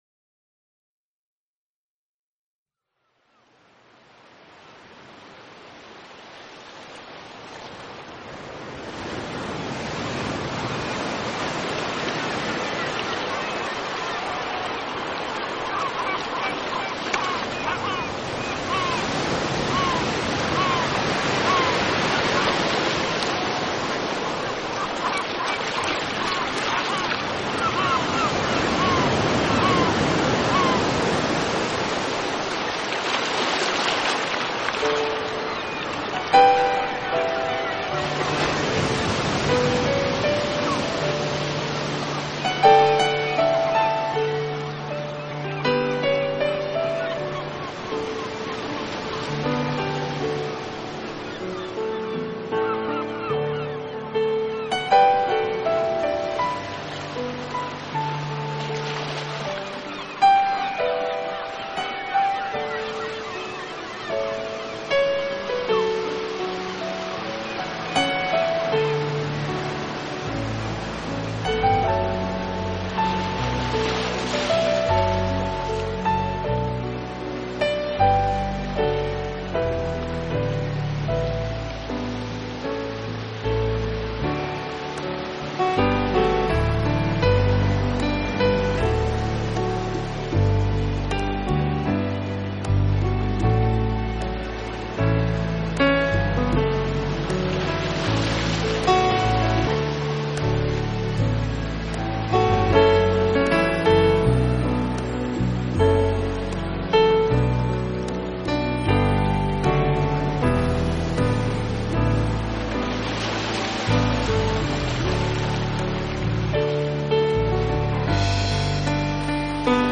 这是一套非常经典的老曲目经过改编用钢琴重新演绎的系列专辑。
本套CD全部钢琴演奏，